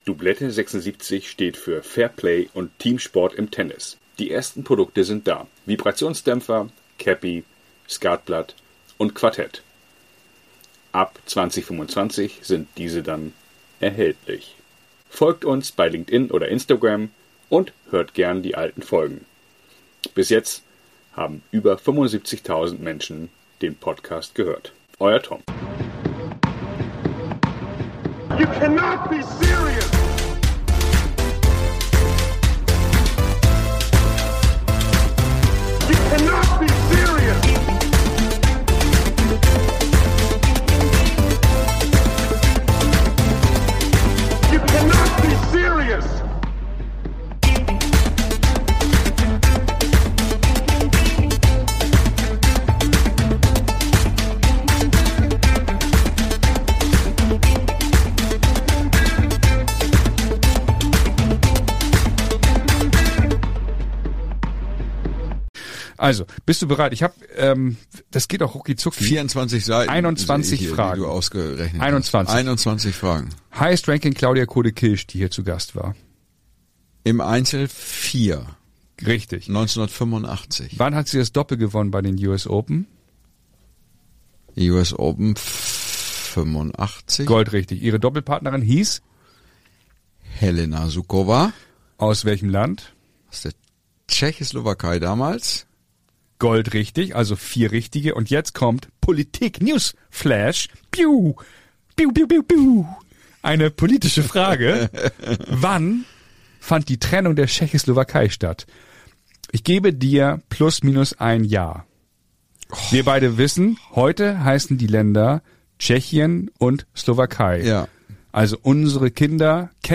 Ex-Profis, Trainer, Ex-Talente, Journalisten - bekannte und unbekannte Tennisspieler erzählen ihre unterhaltsamen Anekdoten des weißen Sports.
Aufgenommen im feinen Hamburger Hotel Tortue oder remote.